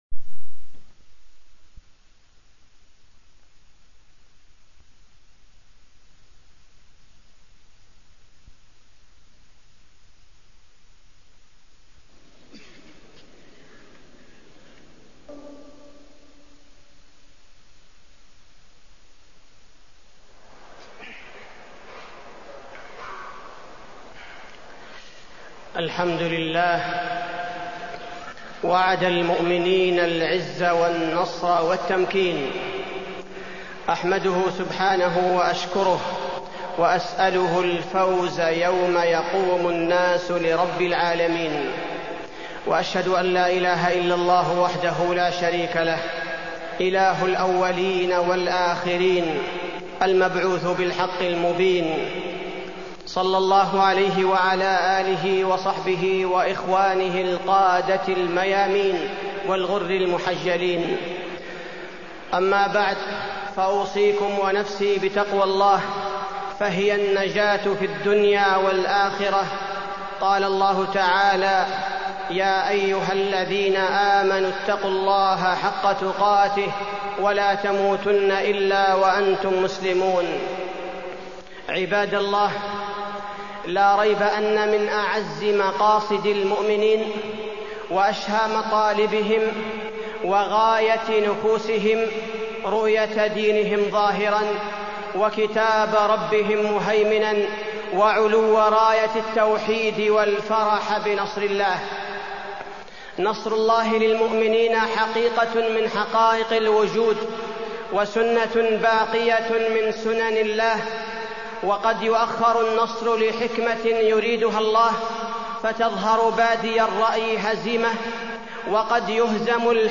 تاريخ النشر ١٢ ربيع الأول ١٤٢٣ هـ المكان: المسجد النبوي الشيخ: فضيلة الشيخ عبدالباري الثبيتي فضيلة الشيخ عبدالباري الثبيتي أسباب النصر